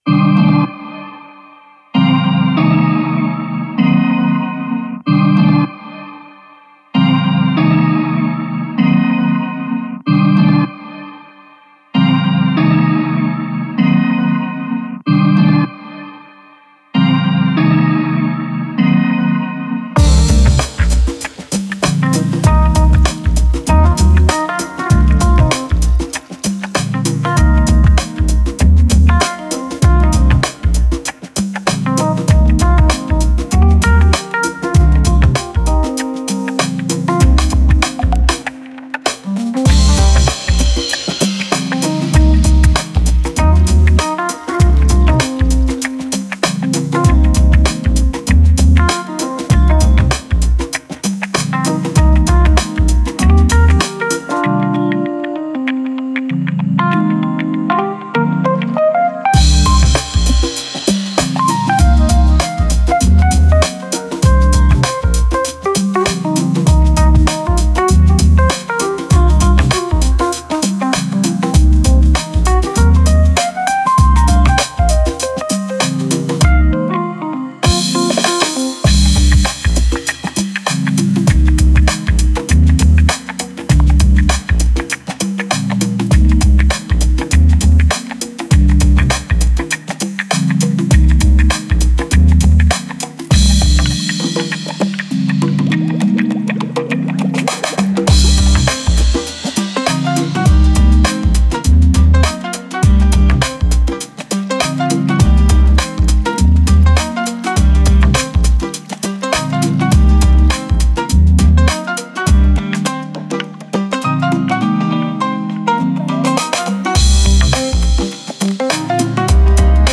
Genre Space